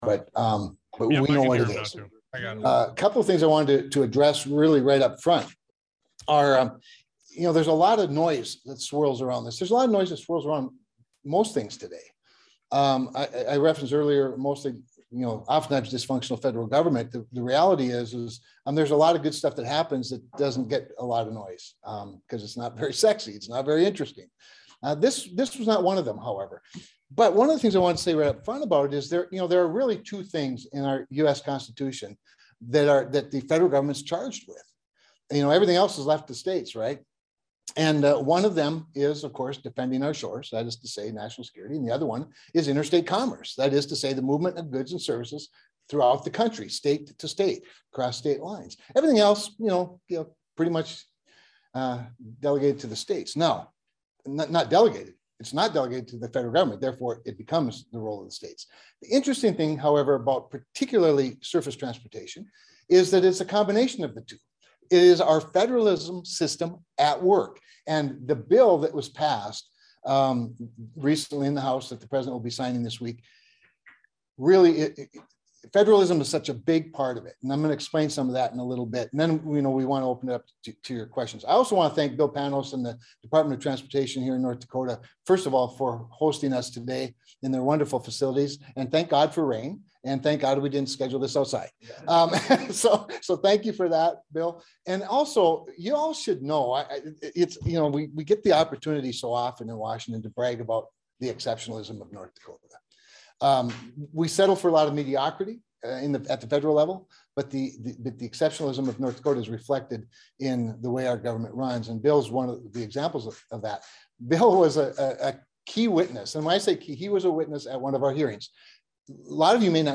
U.S. Senator Kevin Cramer (R-ND), Ranking Member of the Senate Environment and Public Works (EPW) Subcommittee on Transportation and Infrastructure, hosted a press conference to discuss the Infrastructure Investment and Jobs Act, which has now passed both chambers of Congress.